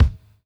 Index of /90_sSampleCDs/Drumdrops In Dub VOL-1/SINGLE HITS/DUB KICKS
DUBKICK-04.wav